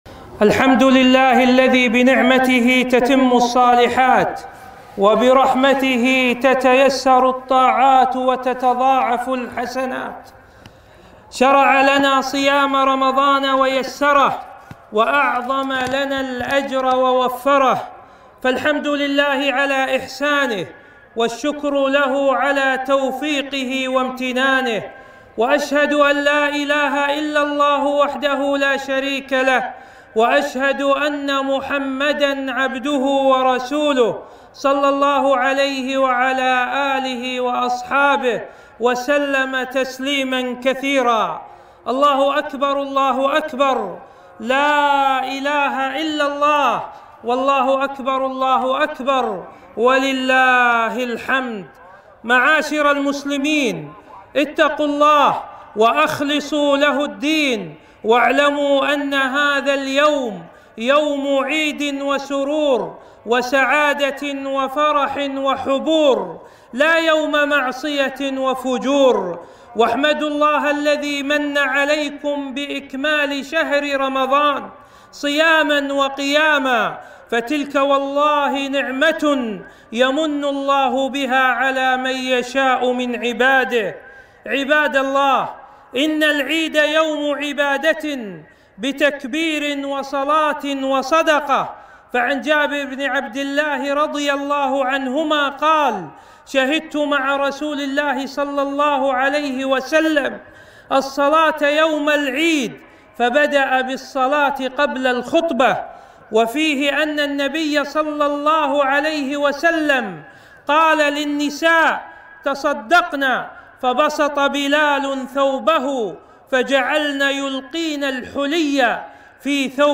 خطبة عيد الفطر 1443هــ أهمية التوحيد - المخالفات في العيد